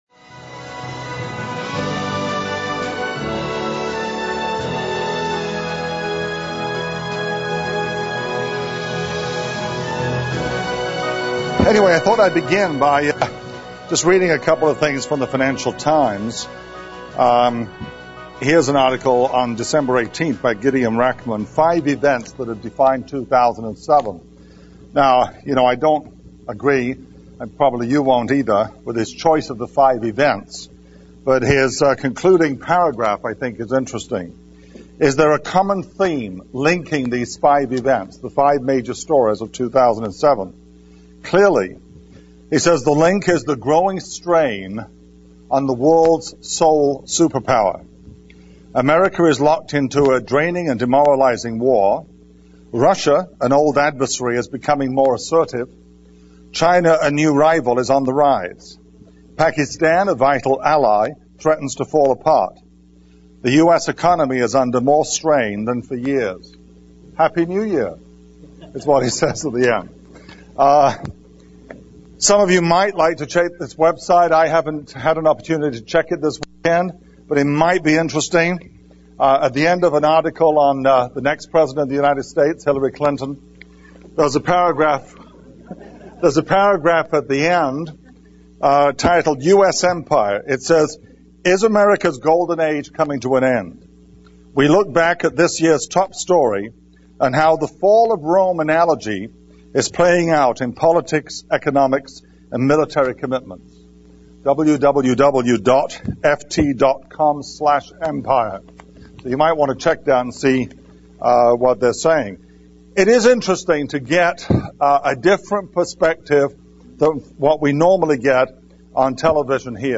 World News and Prophecy Seminar